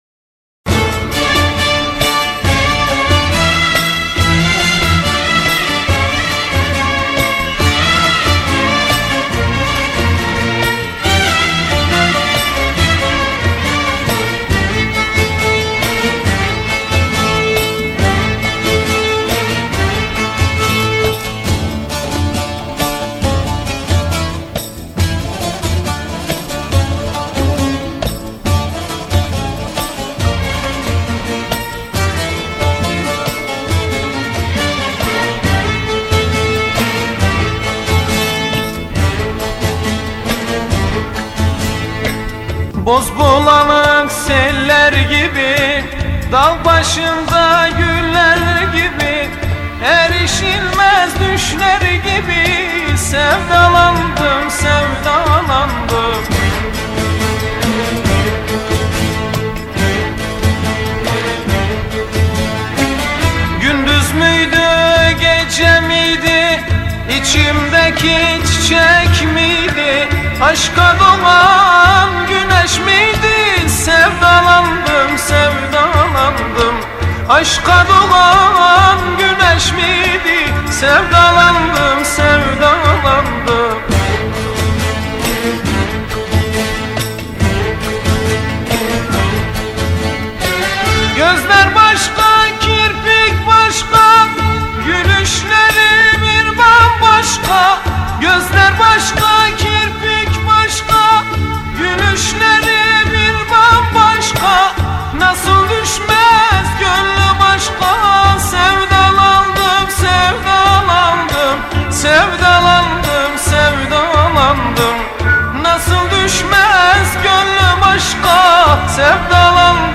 Arabesk, Turkish Pop